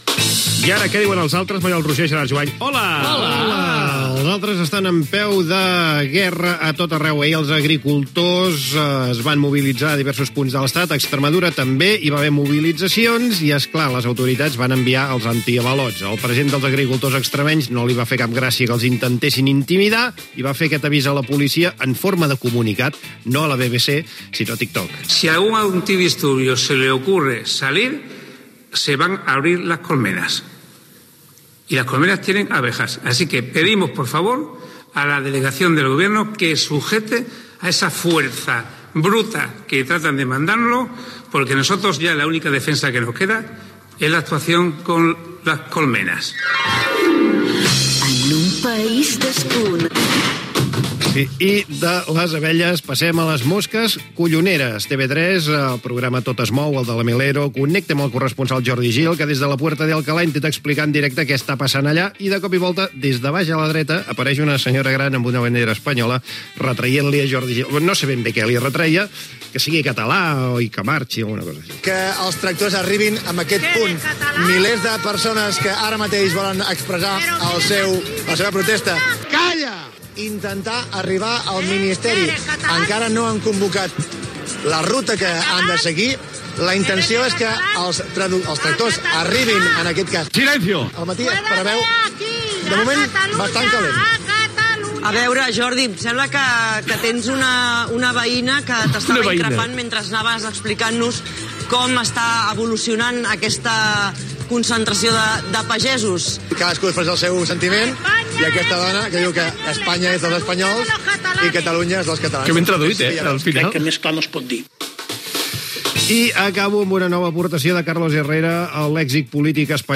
Els treballadors del sector primari d'Extremadura avisen: si les autoritats els envien antiavalots per dissoldre les protestes, no dubtaran a fer servir les abelles per contraatacar. Una ciutadana de Madrid increpa a un redactor de TV3 al carrer. Comentaris del president de la Generalitat Pere Aragonès.
Info-entreteniment